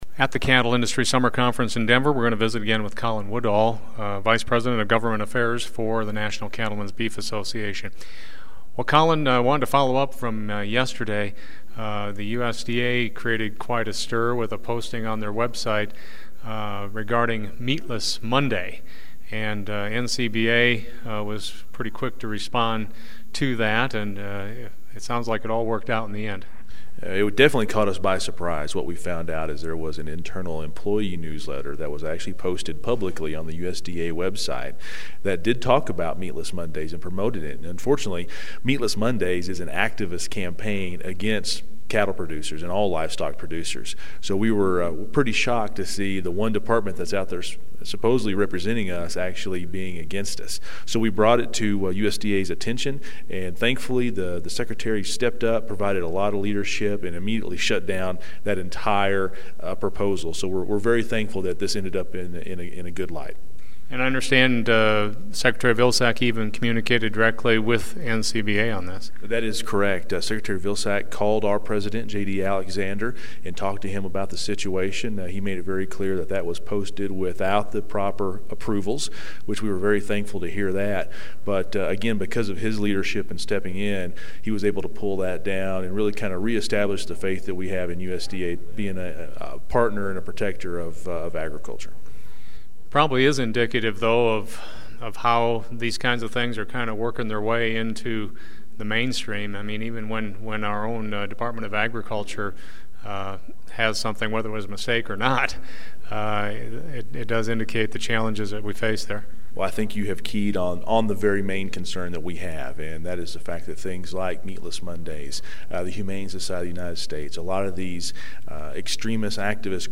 In an interview at the Cattle Industry Summer Conference in Denver